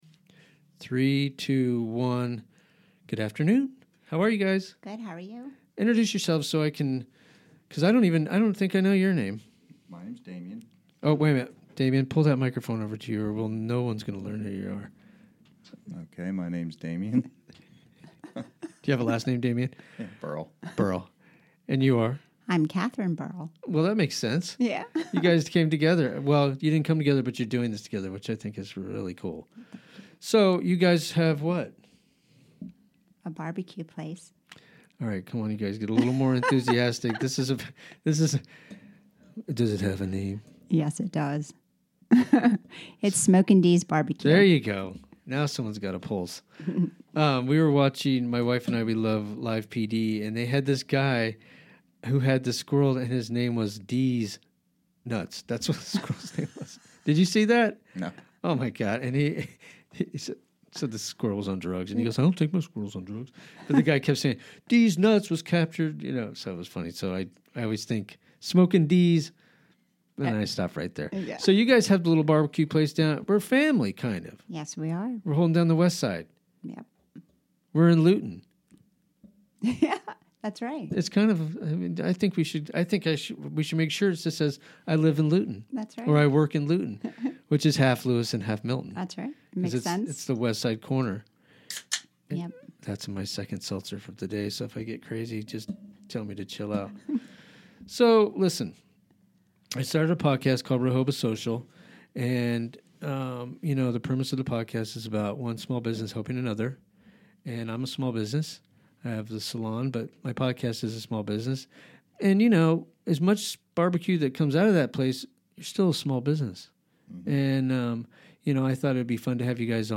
Chatting with local folks